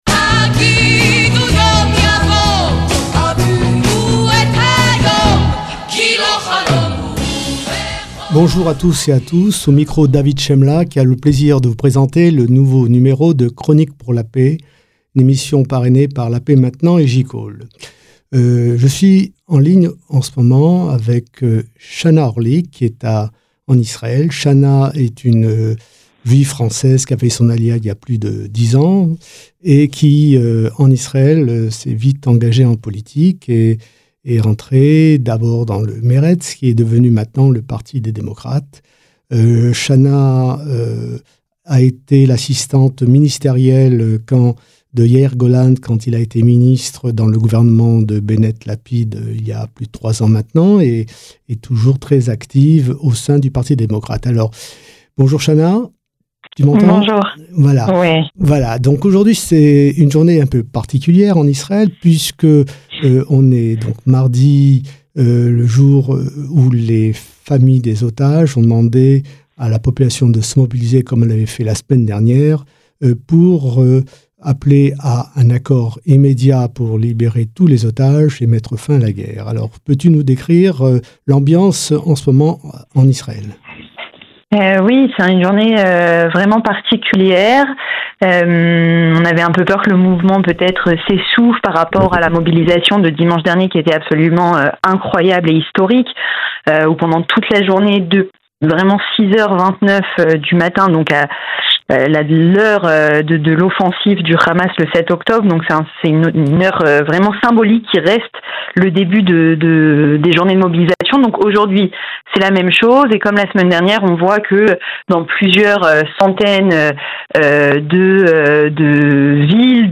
émission bimensuelle sur Radio Shalom